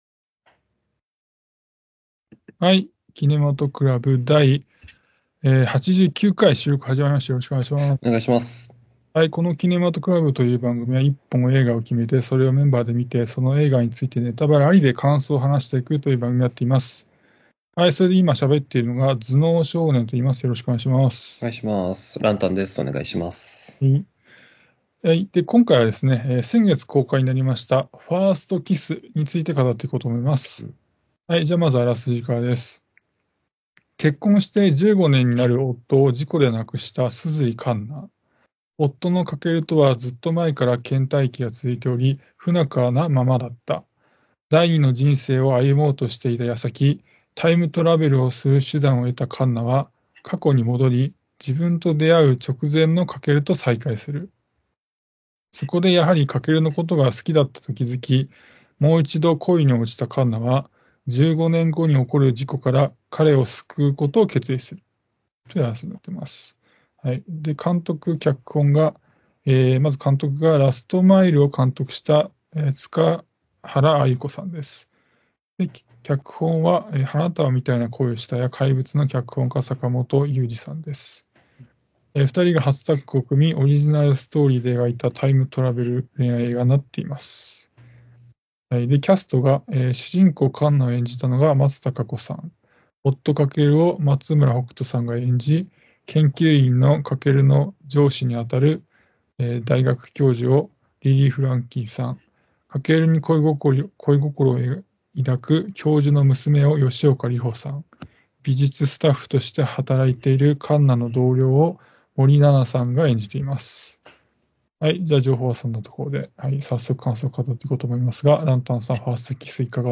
映画好きの男達が毎回映画の課題作を決め、それを鑑賞後感想を話し合います。